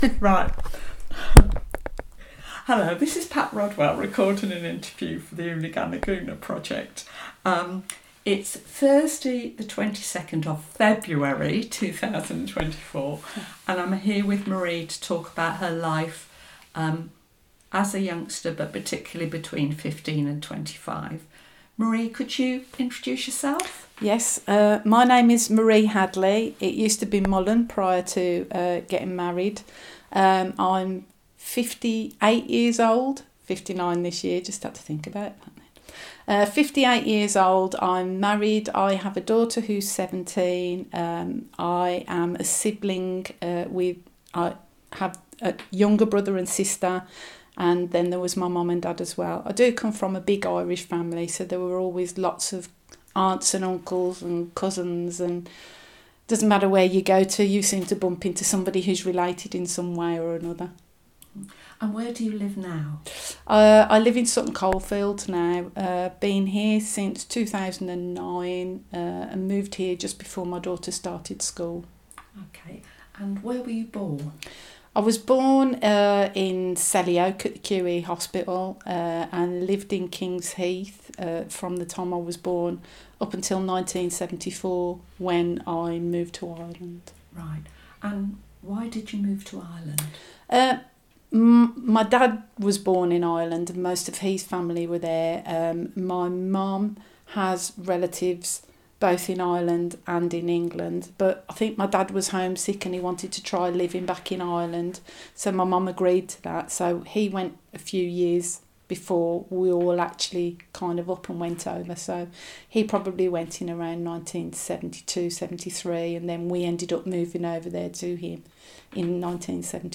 interview
recorded in Birmingham